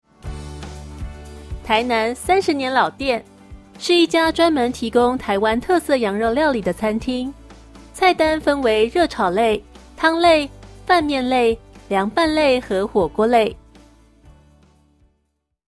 中文語音解說